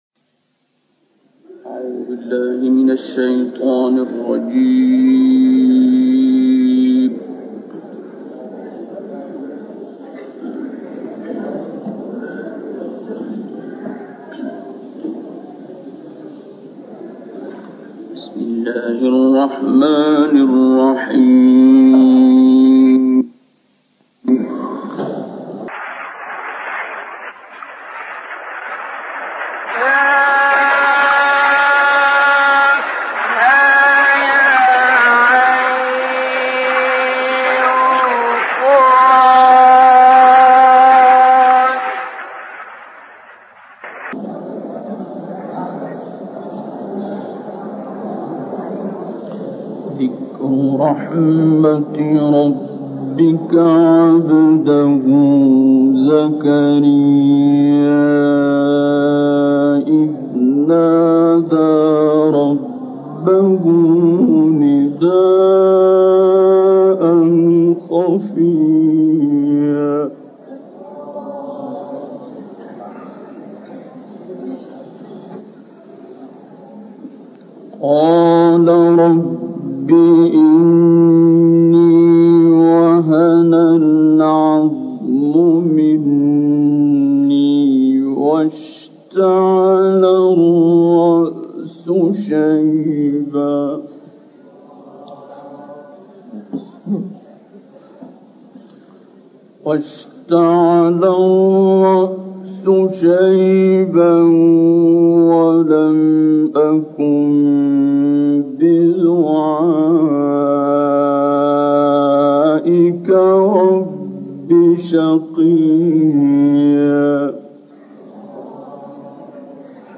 تلاوت آیاتی از سوره مریم و تکویر با صدای استاد عبدالباسط